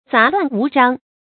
雜亂無章 注音： ㄗㄚˊ ㄌㄨㄢˋ ㄨˊ ㄓㄤ 讀音讀法： 意思解釋： 雜亂：多而亂；無章：沒有條理。